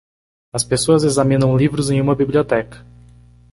Pronúnciase como (IPA)
/bi.bli.oˈtɛ.kɐ/